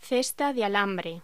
Locución: Cesta de alambre
voz